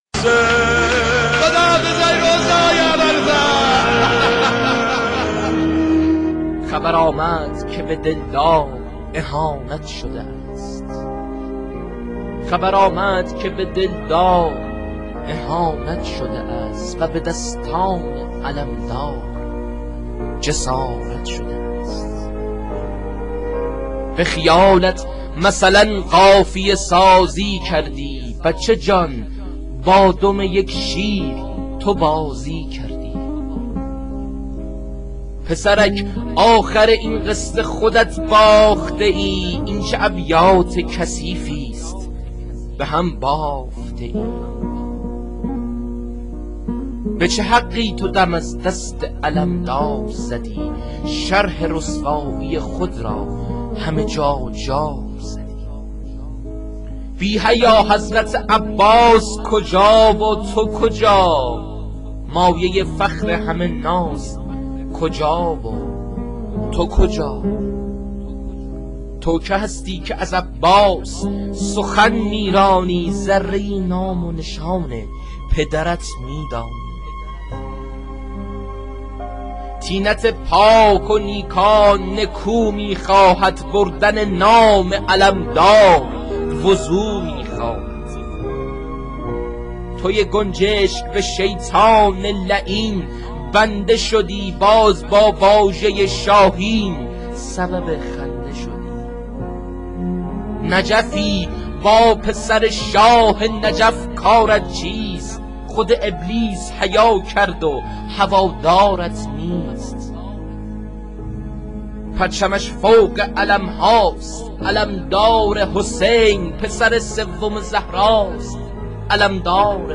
شعرخوانی